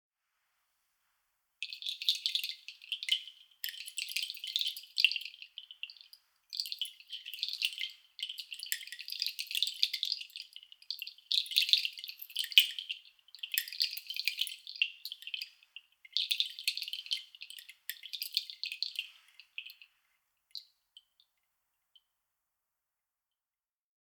Meinl Sonic Energy Flower of Life Kenari Chimes Small - 6 Cords (SKFOLS)